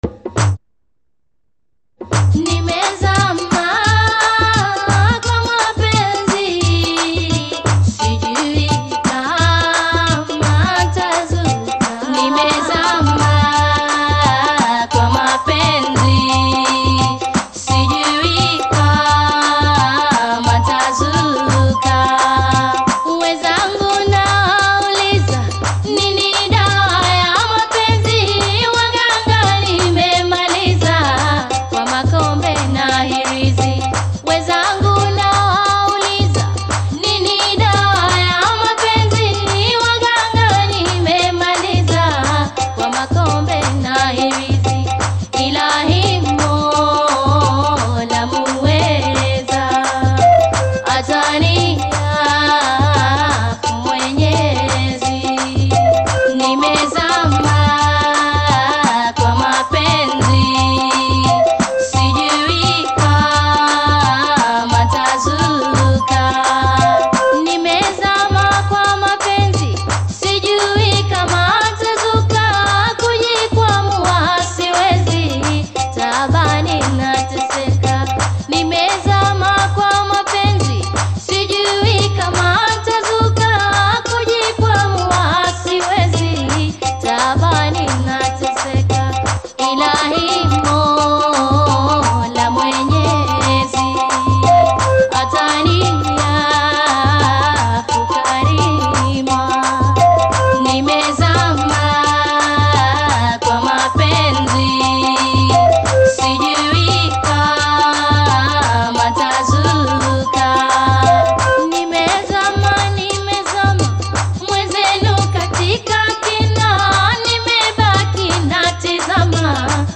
Bongo Fleva Kitambo